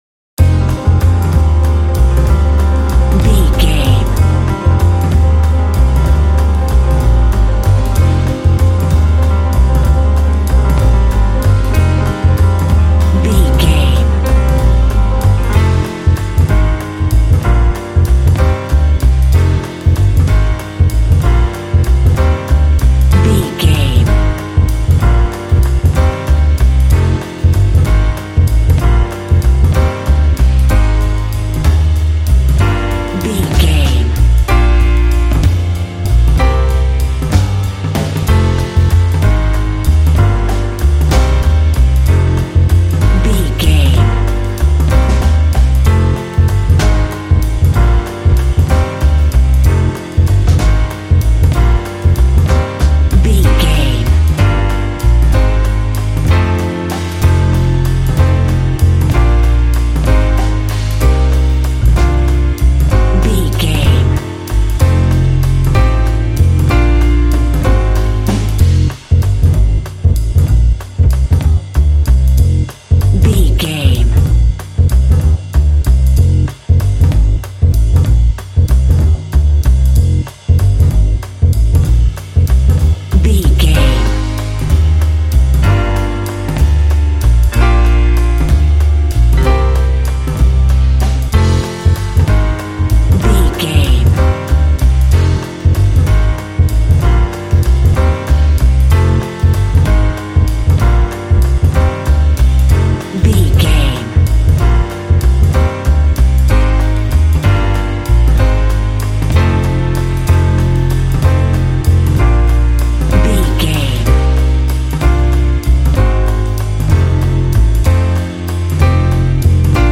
Smooth jazz track reminiscent of classic jazz standards.
Aeolian/Minor
melancholy
cool
smooth
double bass
drums
piano
jazz
swing